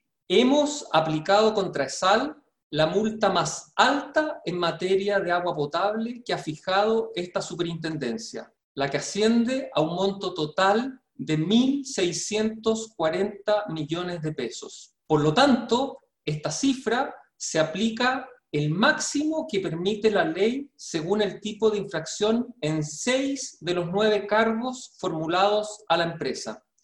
En entrevista con Radio Sago, el Superintendente de Servicios Sanitarios, Jorge Rivas, se refirió a la multa que se le impuso a la empresa Essal por el masivo corte del servicio de agua potable en Osorno ocurrido en el 2019.